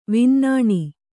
♪ vinnāṇi